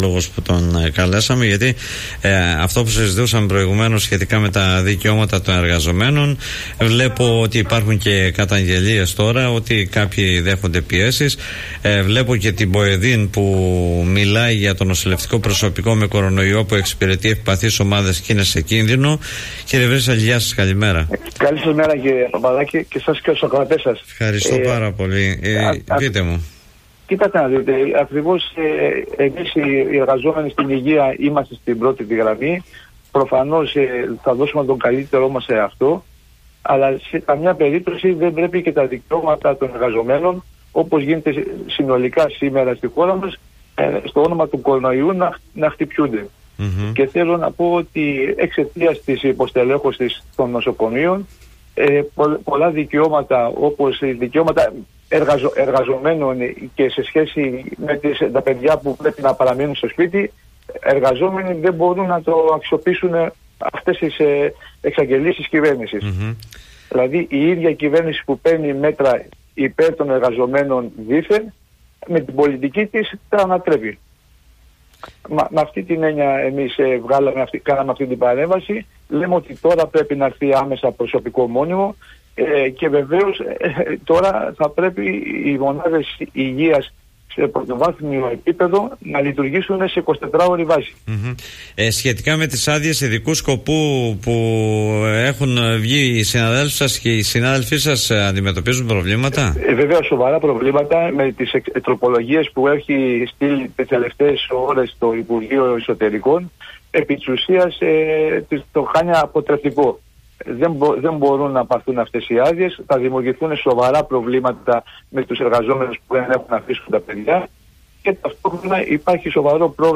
“Να μην μπουν τα εργασιακά μας δικαιώματα σε καραντίνα”, τόνισε στον Politica 89.8